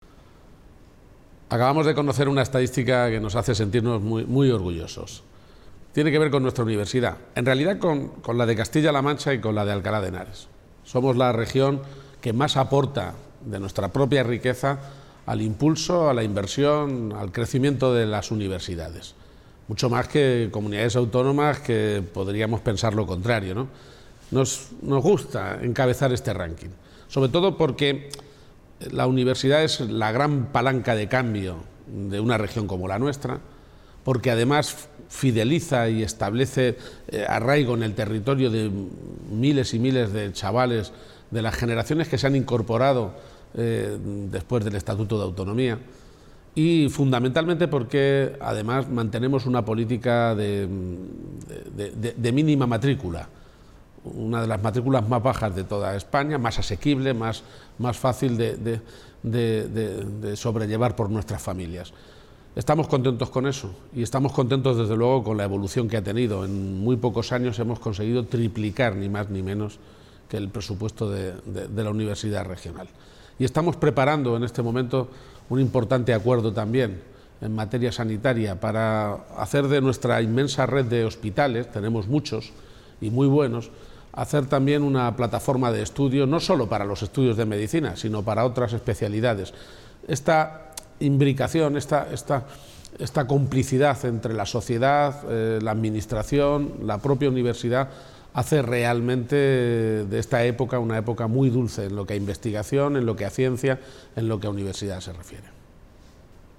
>> Así lo ha indicado hoy el presidente García-Page en un video en sus redes sociales